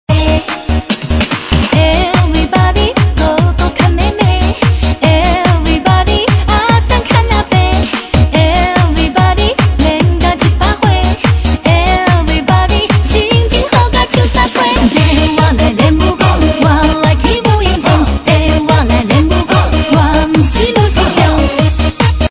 DJ铃声 大小